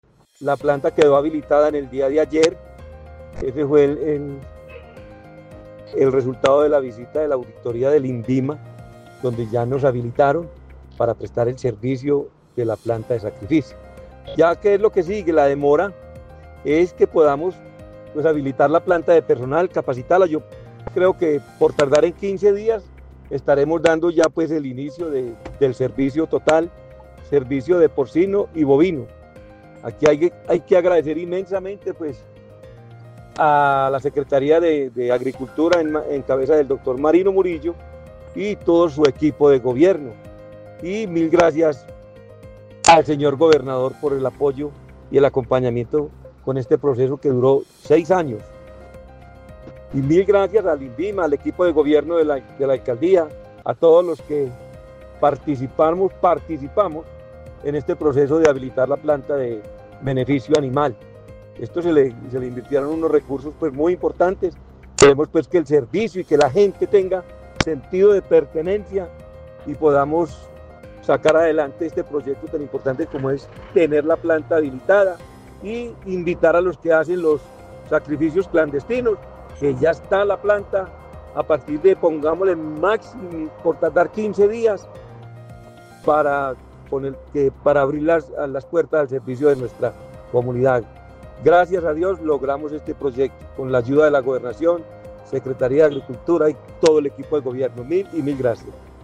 Carlos Enrique Botero Álvarez, alcalde de Manzanares.